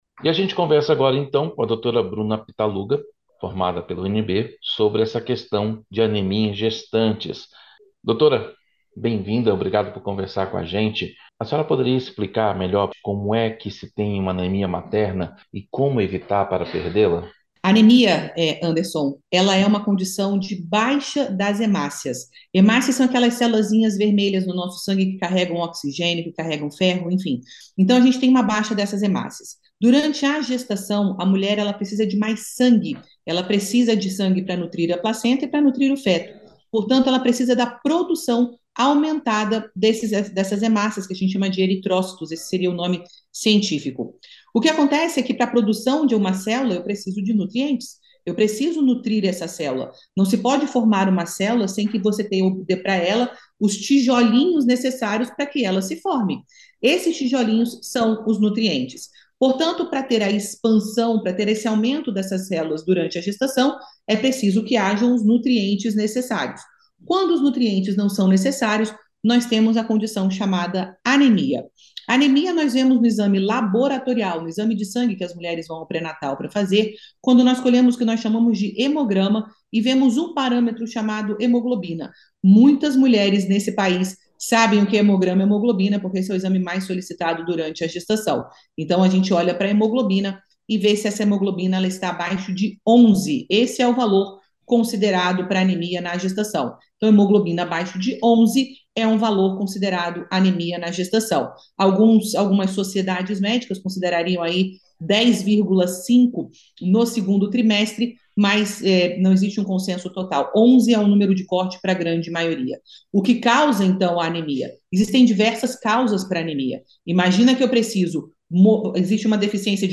Aqui você escuta as entrevistas feitas pela equipe da Rádio Senado sobre os assuntos que mobilizam o país e sobre as propostas que estão sendo discutidas no Parlamento.